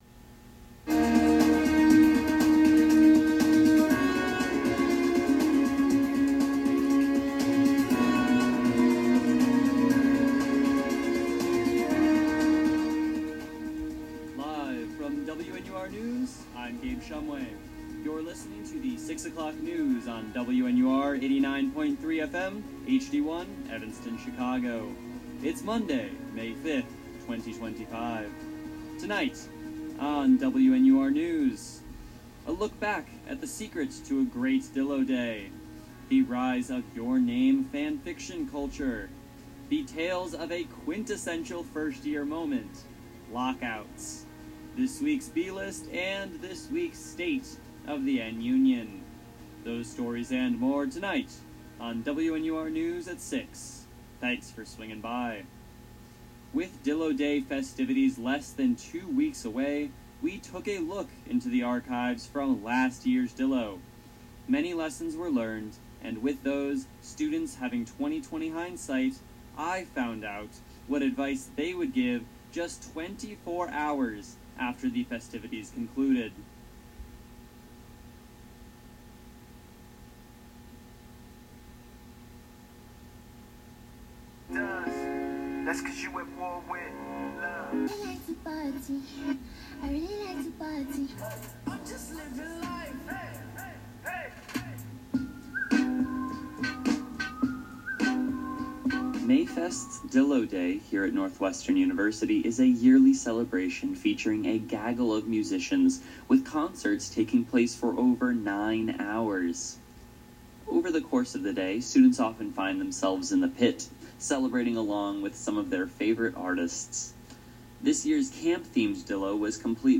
May 5, 2025: A LOOK BACK AT THE SECRET TO A GREAT DILLO DAY, THE RISE OF YOUR NAME FANFICTION CULTURE, THE TALES OF A QUINTESSENTIAL FIRST-YEAR MOMENT: LOCKOUTS, THIS WEEK’S B-LIST, AND THIS WEEK’S STATE OF THE NU-NION. WNUR News broadcasts live at 6 pm CST on Mondays, Wednesdays, and Fridays on WNUR 89.3 FM.